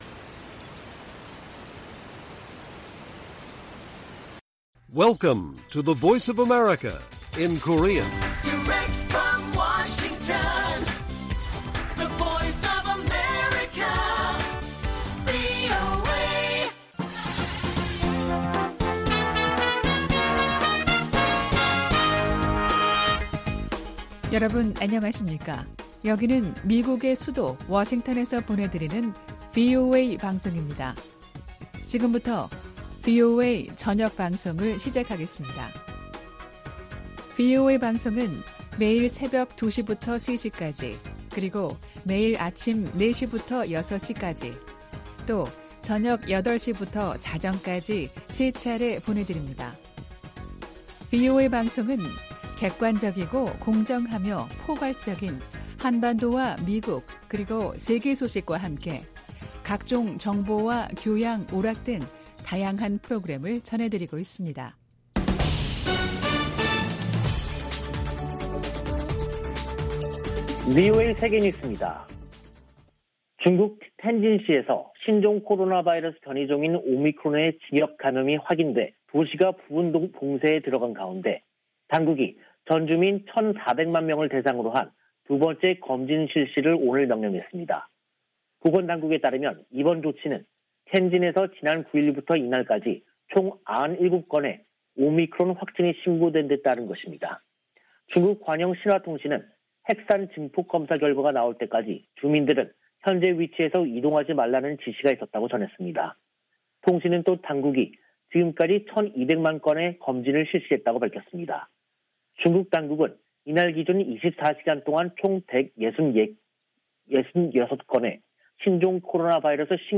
VOA 한국어 간판 뉴스 프로그램 '뉴스 투데이', 2022년 1월 12일 1부 방송입니다. 북한은 11일 쏜 발사체가 극초음속 미사일이었고 최종 시험에 성공했다고 발표했습니다. 백악관이 북한의 최근 미사일 발사를 규탄하면서 추가 도발 자제와 대화를 촉구했습니다. 유엔 사무총장은 북한의 연이은 미사일을 발사를 매우 우려하고 있다고 밝혔습니다.